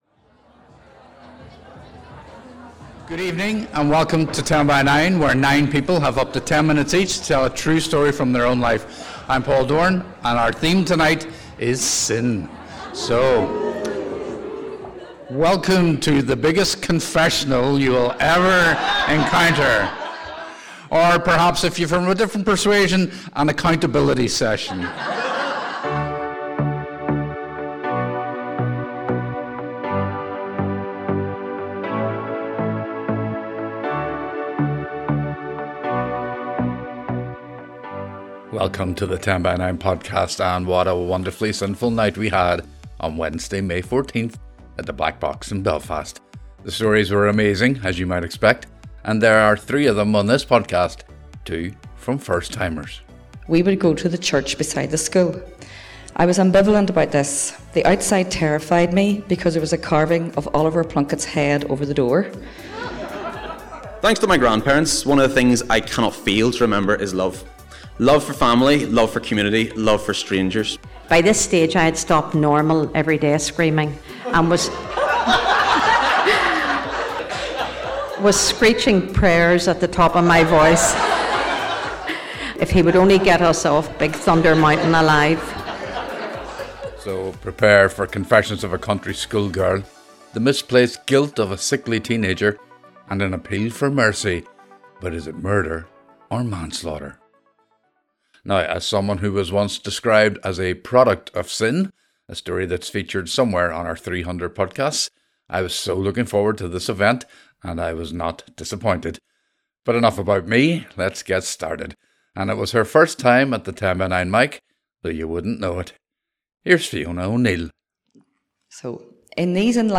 Tenx9 is a storytelling night where 9 people have up to 10 minutes each to tell a real story from their lives.
This podcast brings you a sample of 3 stories from each evening.